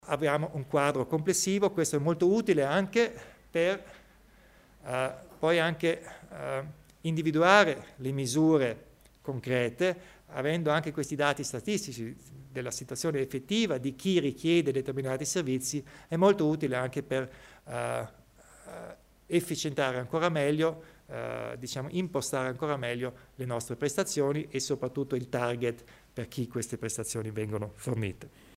Il Presidente Kompatscher spiega le novità in tema di DURP